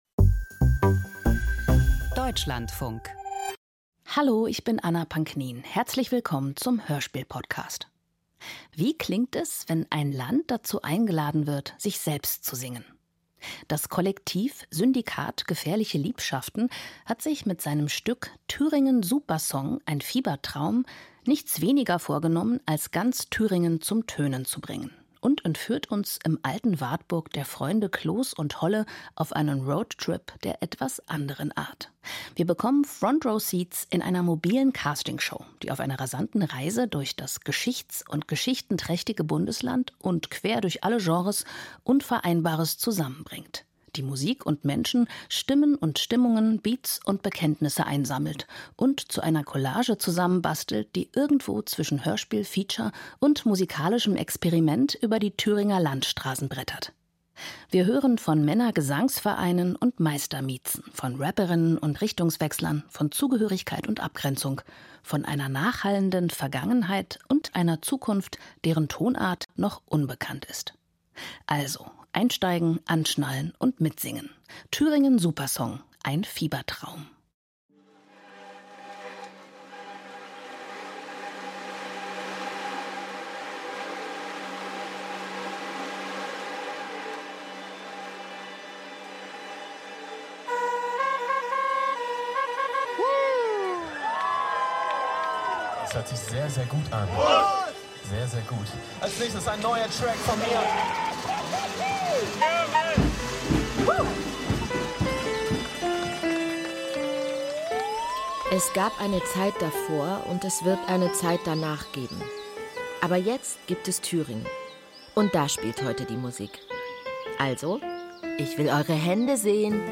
• Musik-Hörspiel • Wonach klingt Thüringen?